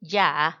yaa[yàa]